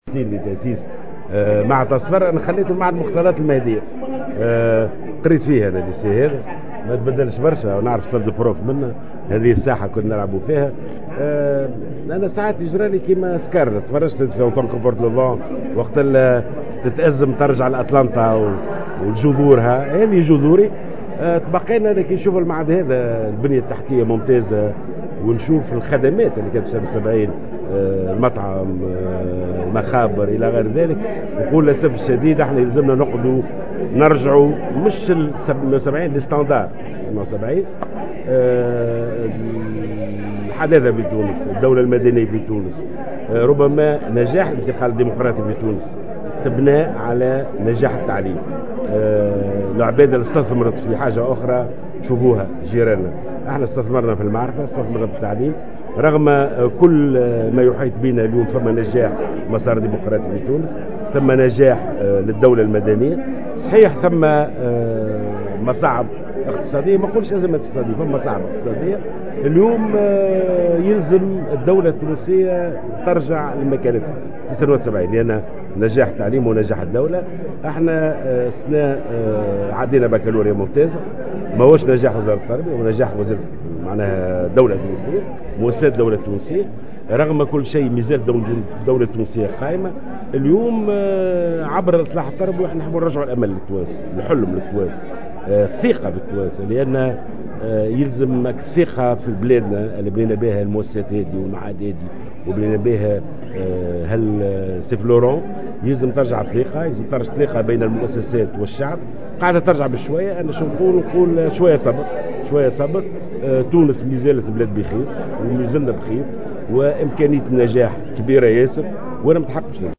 قال وزير التربية ناجي جلول خلال إشرافه على الاحتفال بخمسينية معهد الطاهر صفر بالمهدية مساء اليوم الأحد في تصريح لمراسل الجوهرة "اف ام" إن تونس استثمرت في المعرفة والعلم ونجحت في استثمارها والدليل على ذلك هو نجاح مرحلة الإنتقال الديمقراطي على حد قوله.